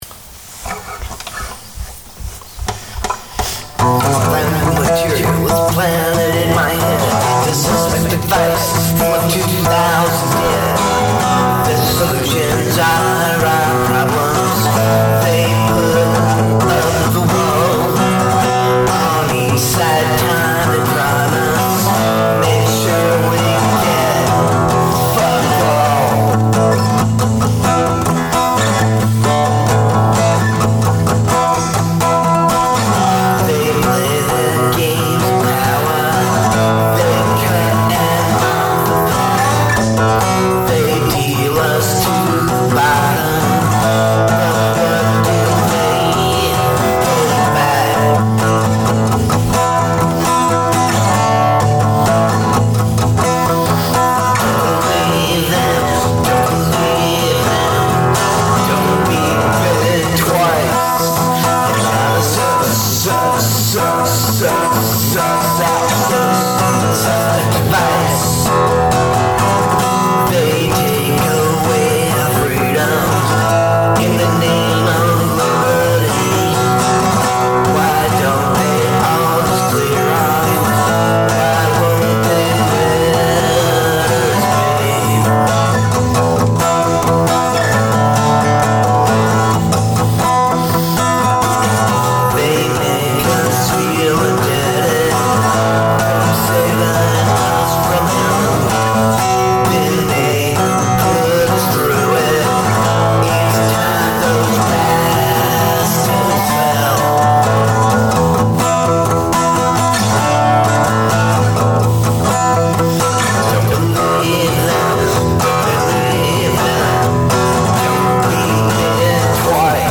this recording is left bare like it should be…)